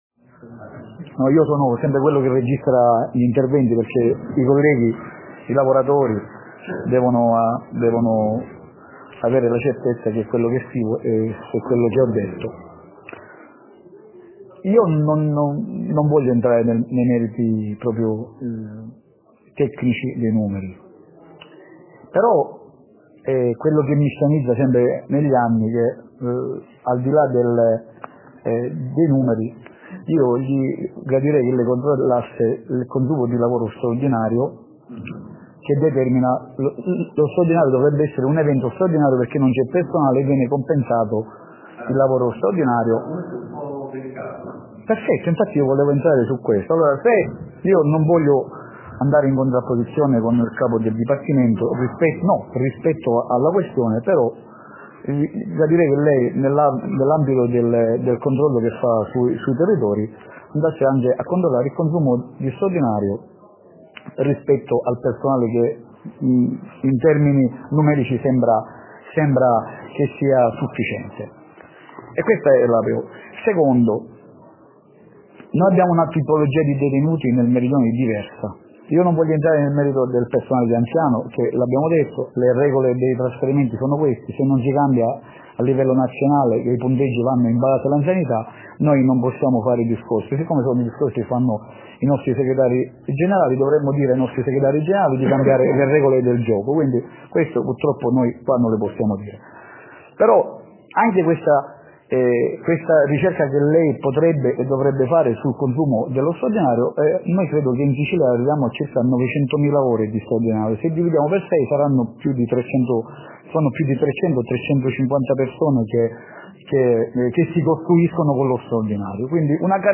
L'INTERVENTO
incontro capo dal del 29 novembre scuola catania.mp3